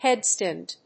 アクセント・音節héad・stànd